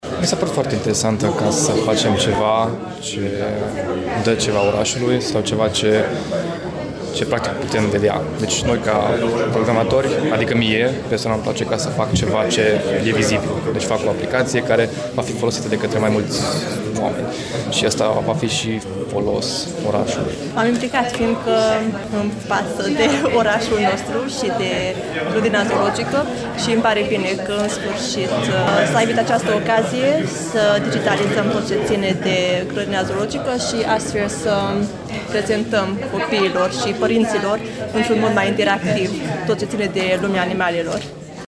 Voluntarii care s-au înscris pentru a construi aplicaţia spun că au răspuns apelului organizatorilor deoarece îşi doresc să ofere ceva palpabil oraşului: